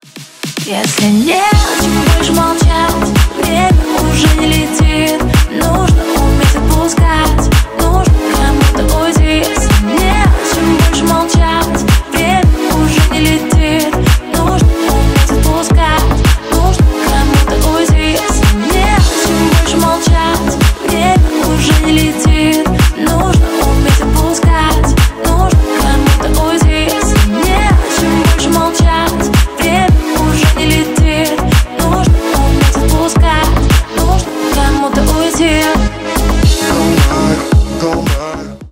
Ремикс # Танцевальные
кавер